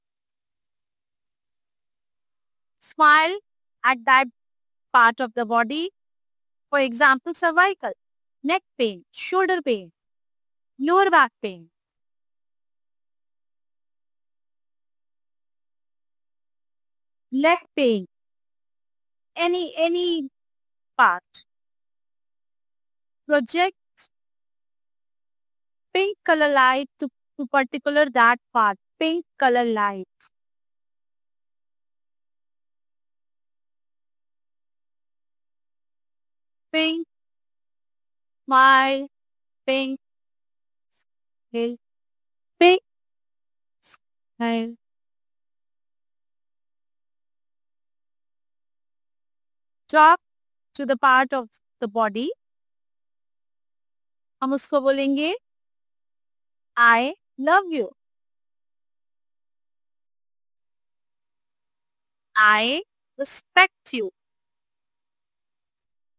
This guided affirmation audio is a gentle yet powerful tool to help you forgive yourself and your body. Designed to dissolve guilt, self-blame, and emotional tension, this audio supports deep inner healing and self-acceptance.
Preview-Forgiveness-Affirmation-Audio-Self-Body.mp3